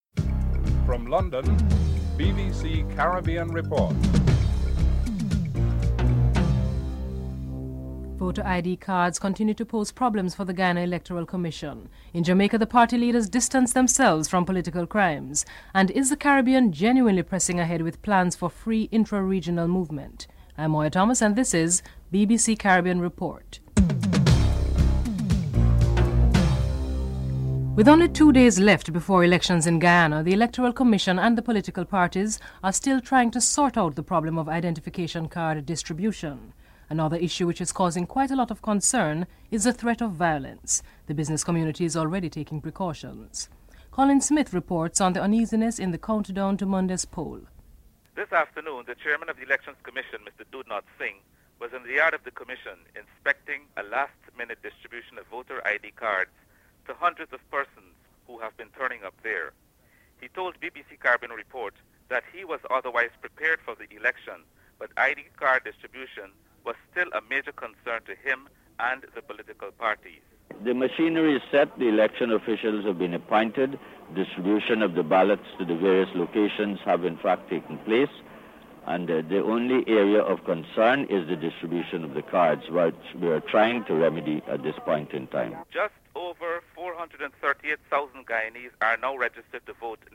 1. Headlines (00:00-00:27)
3. In Jamaica the party leaders distance themselves from political crimes. P.J. Patterson of the People's National Party, Edward Seaga of the Jamaica Labour Party and Bruce Golding of the National Democratic Party are interviewed (02:45-05:23)